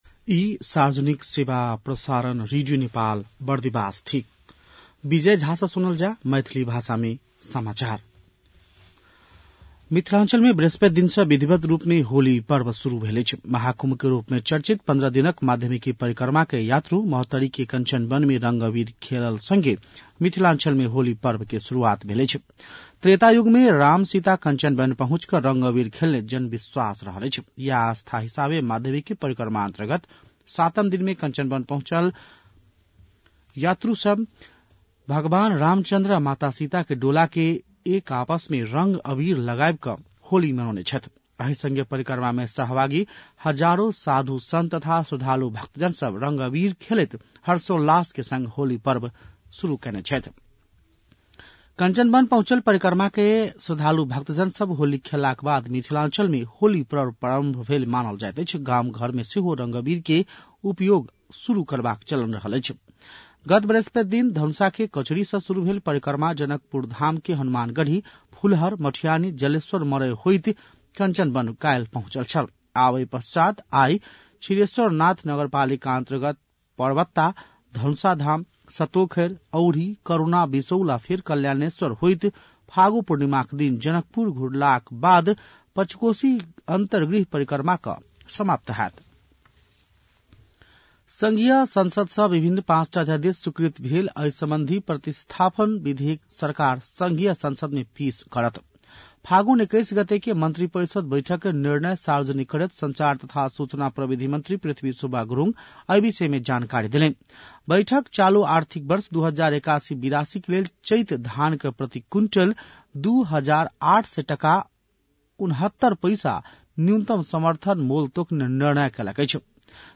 मैथिली भाषामा समाचार : २४ फागुन , २०८१